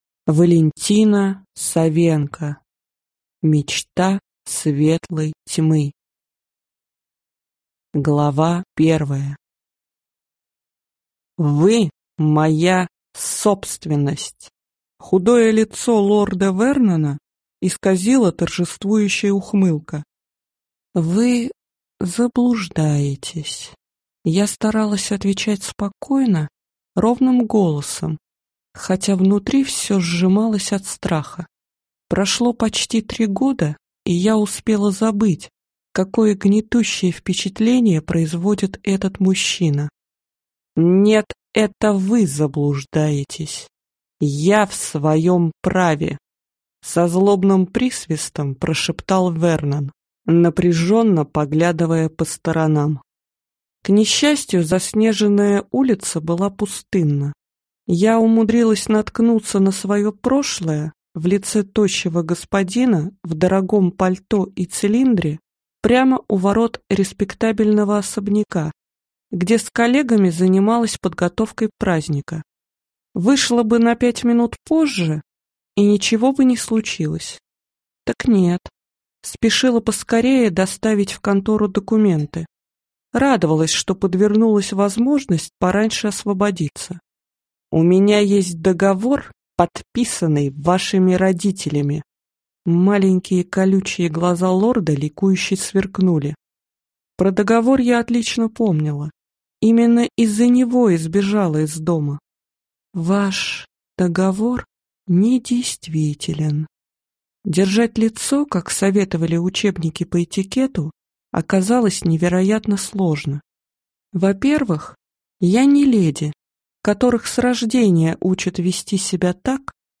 Аудиокнига Мечта светлой тьмы | Библиотека аудиокниг